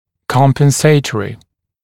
[ˌkɔmpən’seɪtərɪ][ˌкомпэн’сэйтэри]компенсаторный